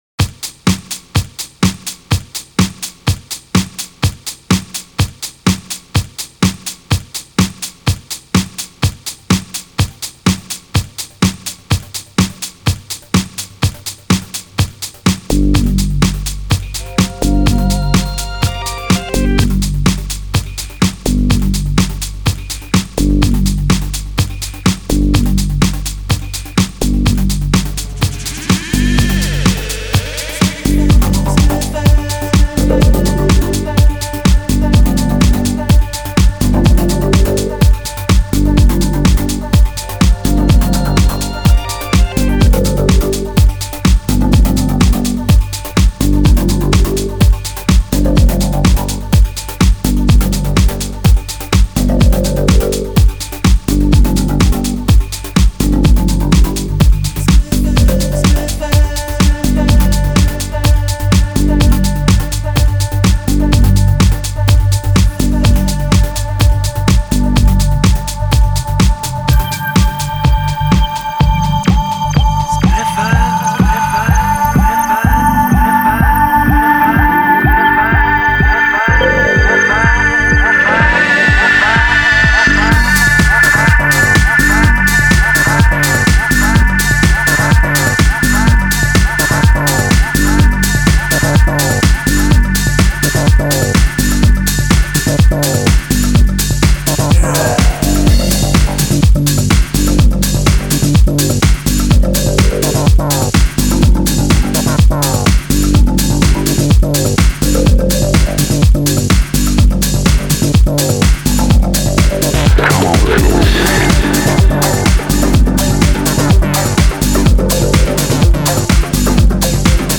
популярный британский певец.